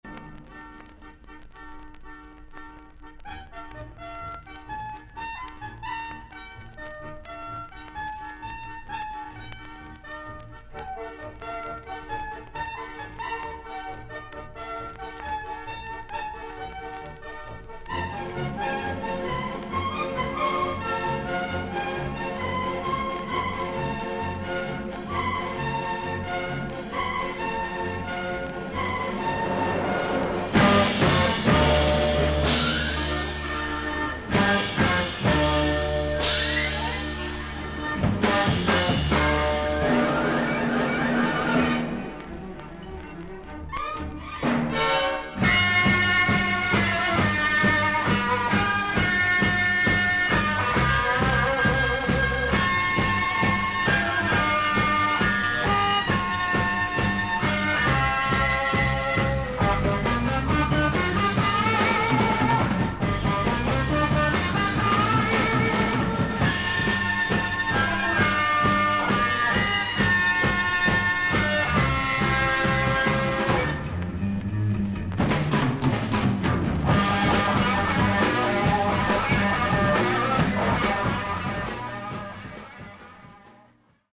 イギリス盤 / 12インチ LP レコード / ステレオ盤
全体的に長短のキズあり。所々に軽いパチノイズの箇所あり。全体的にサーフィス・ノイズあり。